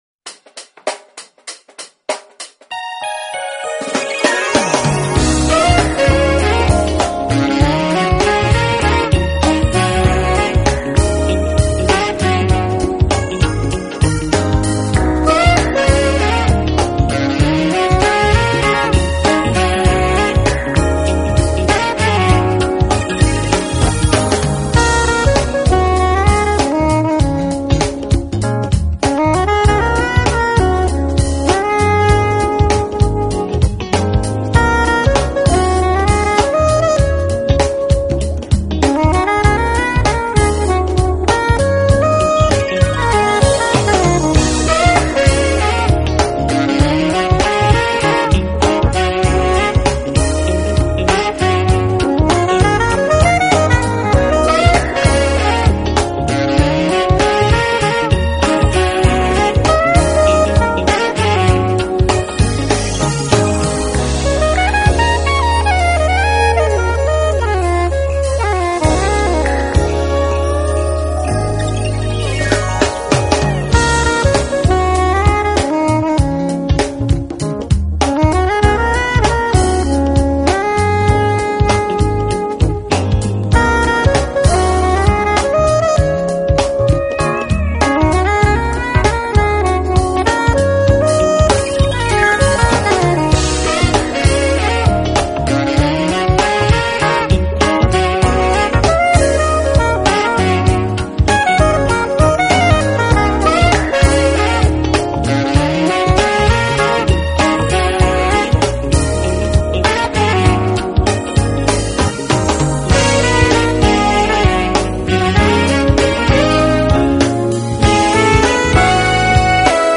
音乐类型：Jazz
是深沉而平静，轻柔而忧伤，奇妙而富有感情。